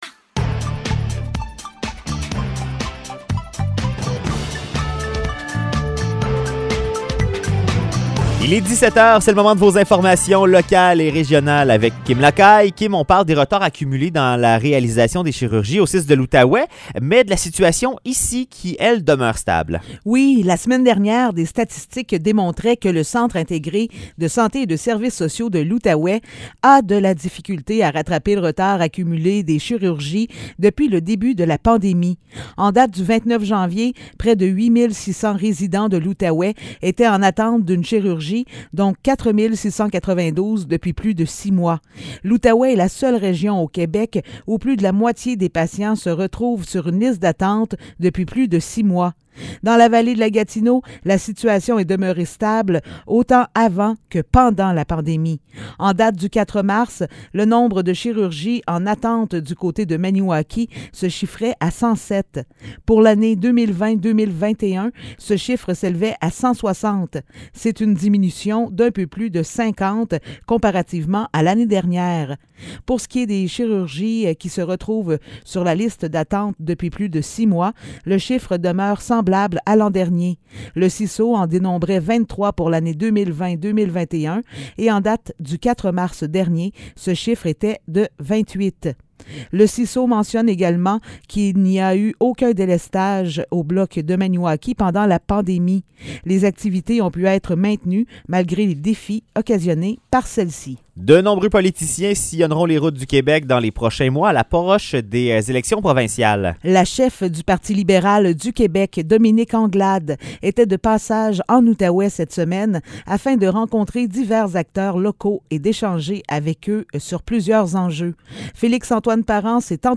Nouvelles locales - 10 mars 2022 - 17 h